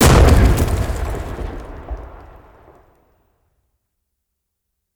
ExplosionBlast_S08WA.111.wav